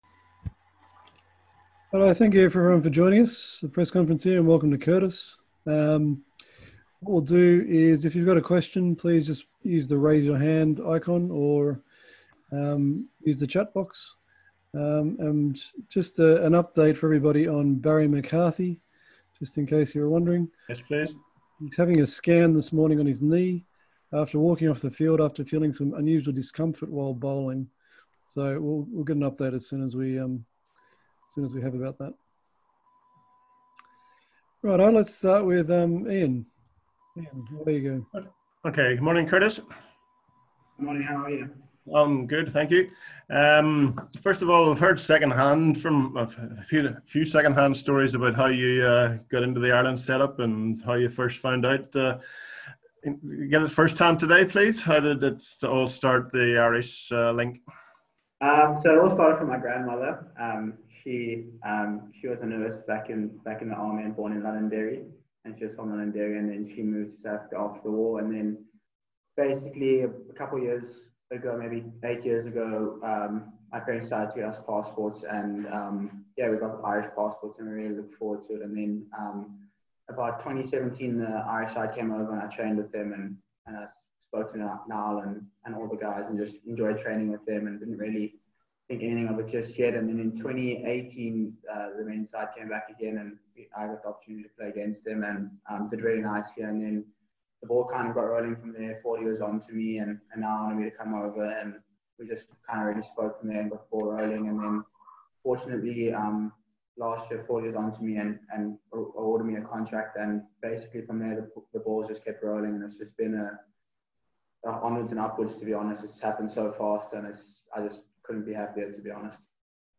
Cricket Ireland ‘virtual’ press conference with Curtis Campher
Curtis Campher spoke to the media - Campher, 21, made his international debut for Ireland yesterday (ODI Cap #60), scoring 59* and taking 1-26.